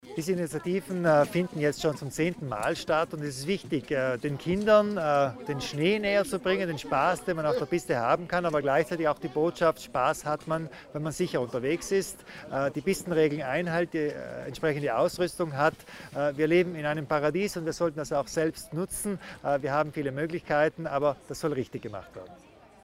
Landeshauptmann Kompatscher zur Bedeutung verantwortungsbewussten Verhaltens in Schnee